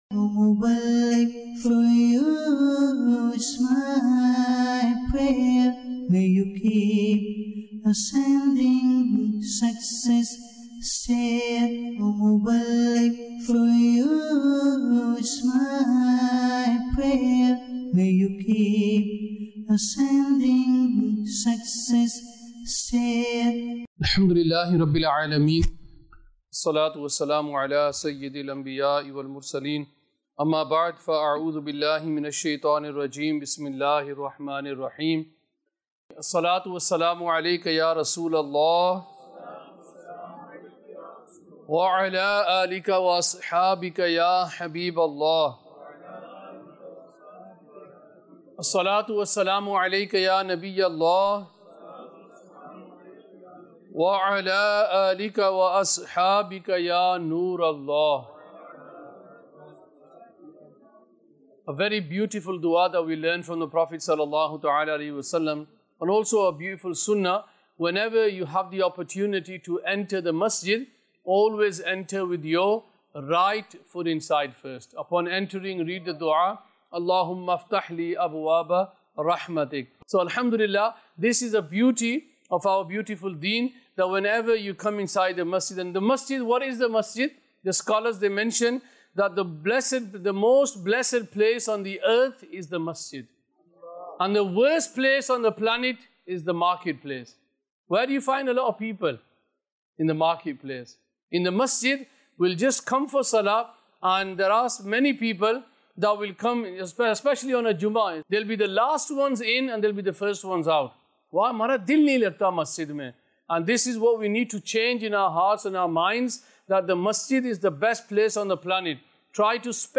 Sunnah Inspired bayan Ep 527 - How To Love ALLAH Almighty